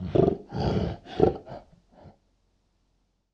PixelPerfectionCE/assets/minecraft/sounds/mob/polarbear/idle4.ogg at mc116